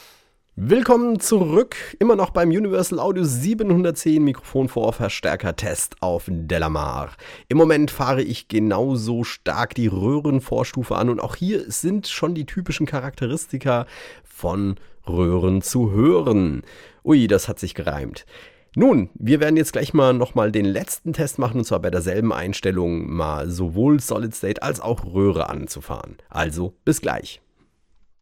In der Röhrenvorstufe hingegen klingt alles etwas runder und wärmer, der Attack wirkt wie etwas »verschmiert«.
In den Klangbeispielen findest Du zahlreiche Aufnahmen mit unterschiedlichen Einstellungen für Gain und Mischungsverhältnis der beiden Schaltkreise.